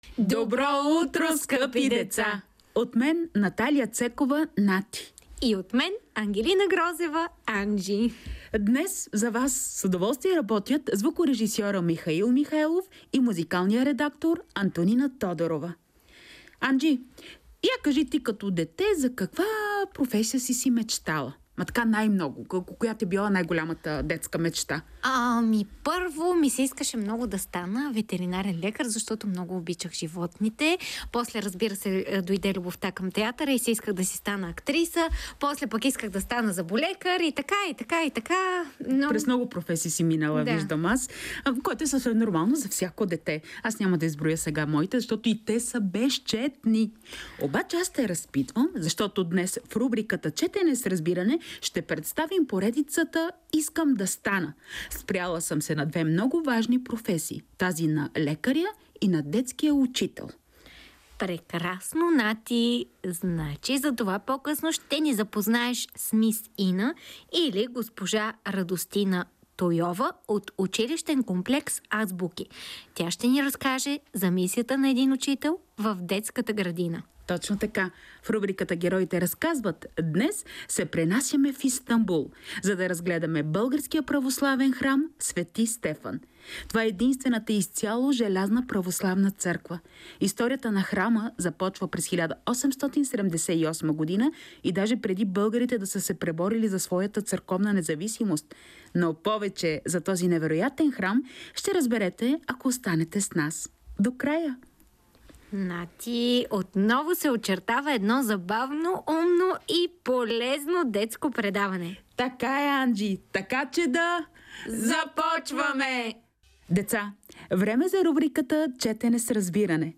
Ще чуете чудатия разговор на една истанбулска котка с православния храм.